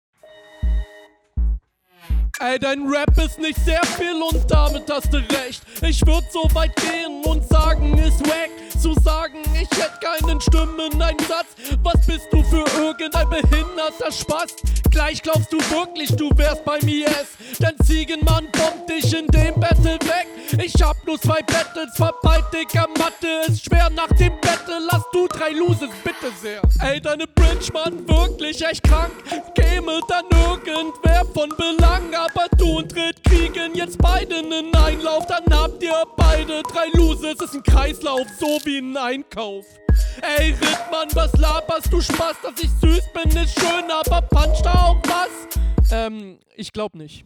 Stimmeinsatz ist kraftvoll, aber wirkt die meiste Zeit leider sehr angestrengt.